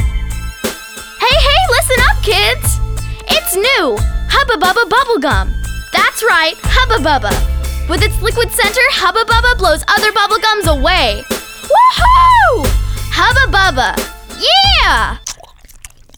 Voice Over Samples